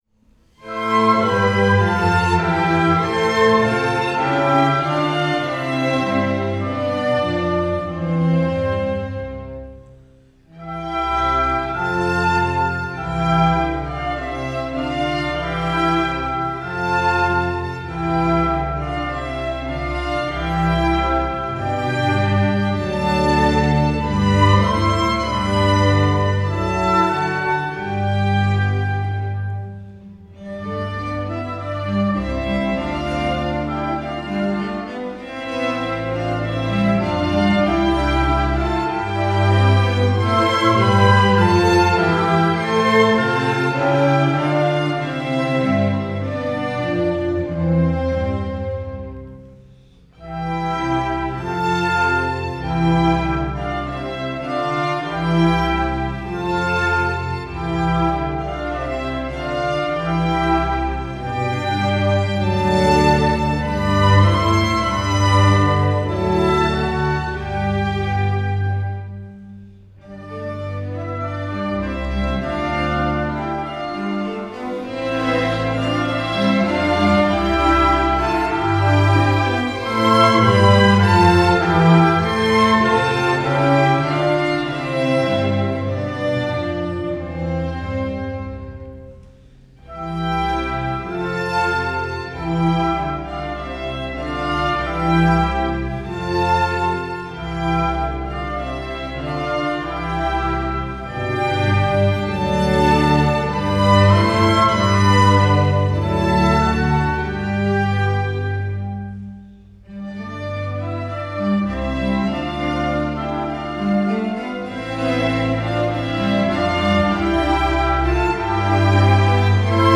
zum Mitsingen (rein instrumental)!